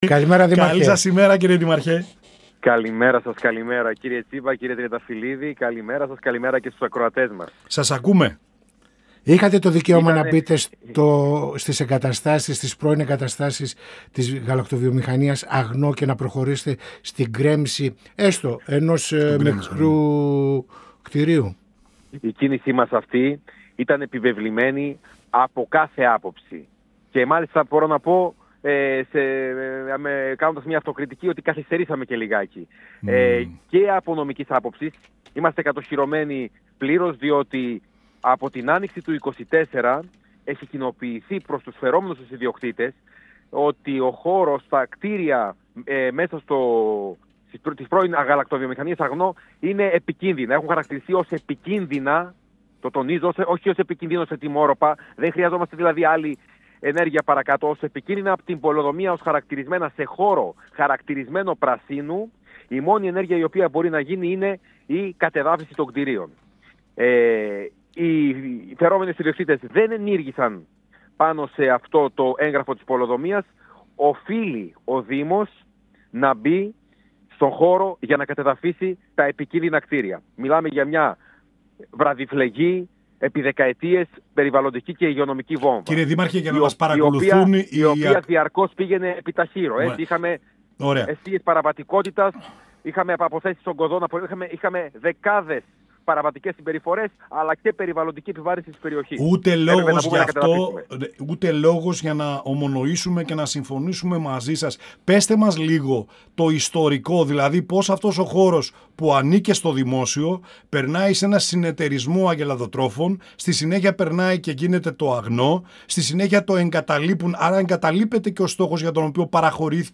Στις εργασίες κατεδάφισης του πρώην εργοστασίου της γαλακτοβιομηχανίας ΑΓΝΟ, που εγκαταλείφθηκε πριν από 33 χρόνια και βρίσκεται στα όρια του Δήμου Παύλου Μελά αναφέρθηκε ο Δήμαρχος Παύλου Μελά Δημήτρης Ασλανίδης, μιλώντας στην εκπομπή «Πανόραμα Επικαιρότητας» του 102FM της ΕΡΤ3.
Συνεντεύξεις